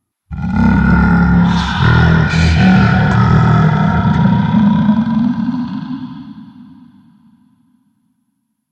Рык